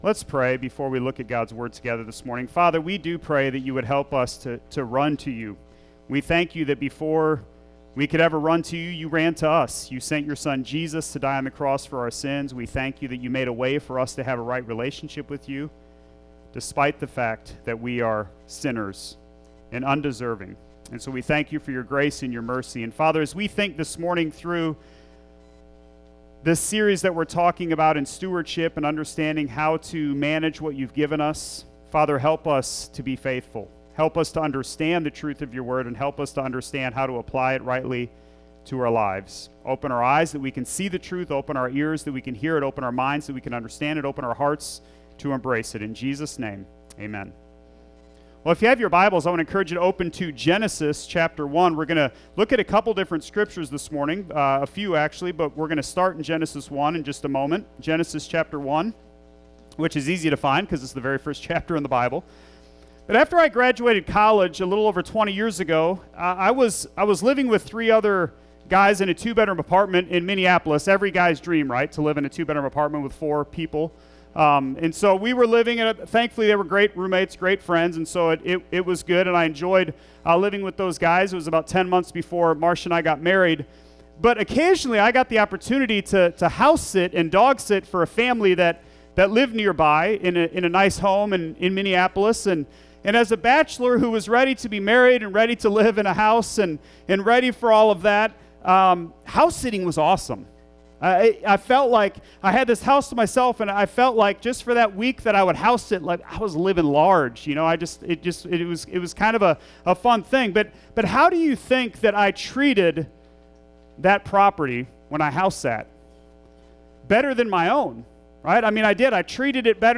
Sermon Audio